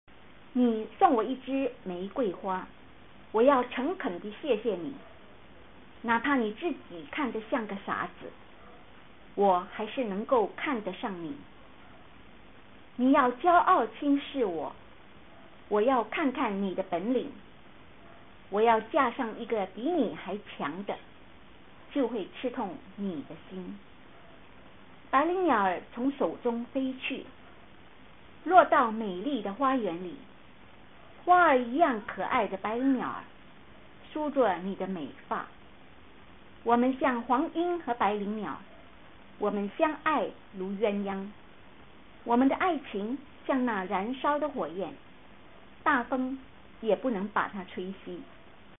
Lyrics in HanYuPinYin read here
GiveRose_LyricsRead.mp3